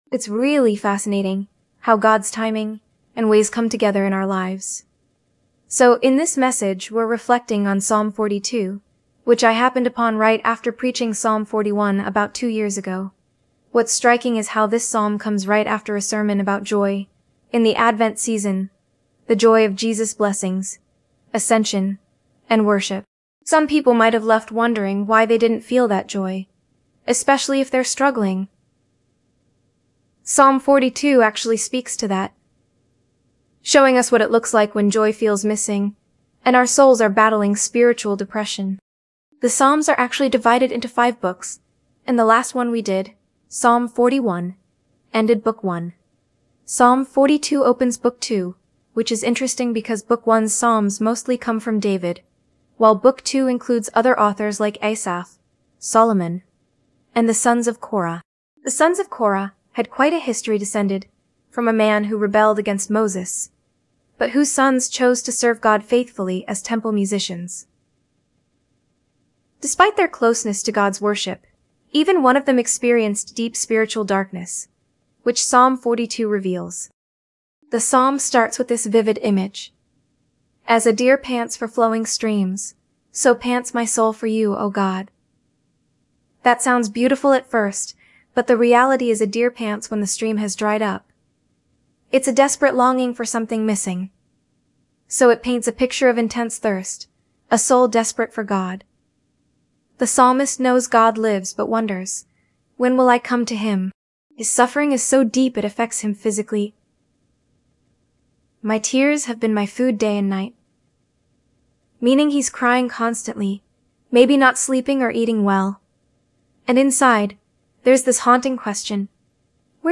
We have overall notes of the sermon, a short podcast of the sermon, and the complete video of the sermon.